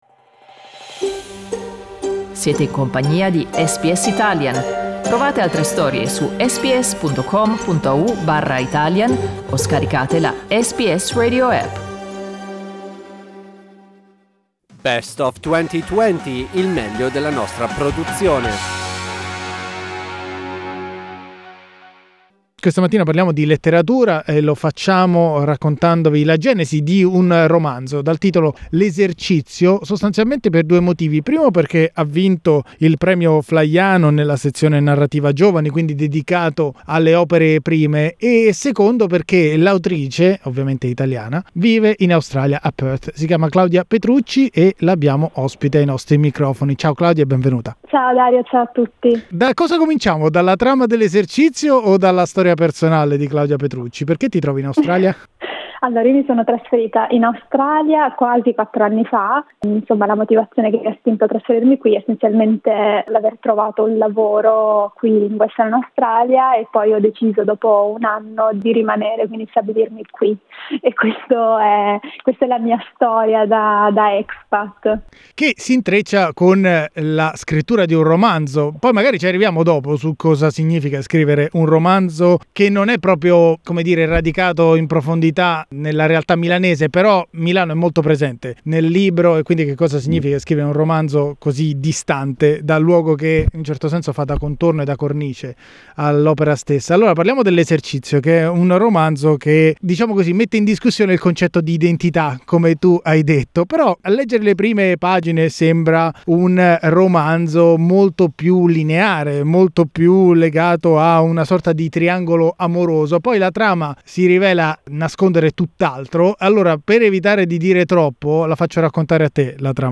Nel mese di luglio del 2020 abbiamo intervistato una giovane scrittrice che da cinque anni vive nel Western Australia.